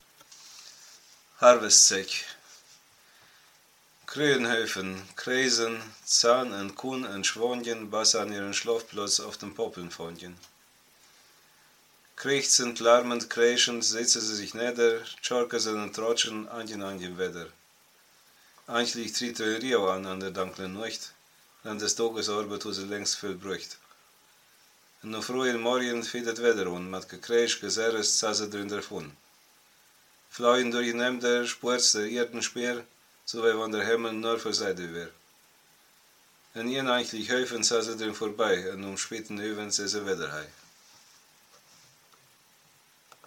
Ortsmundart: Schirkanyen